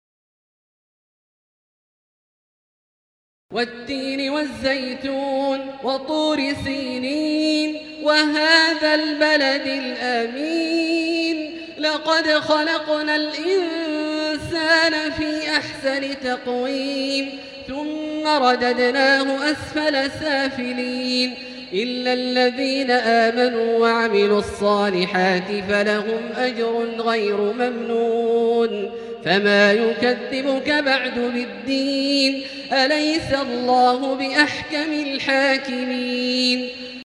المكان: المسجد الحرام الشيخ: فضيلة الشيخ عبدالله الجهني فضيلة الشيخ عبدالله الجهني التين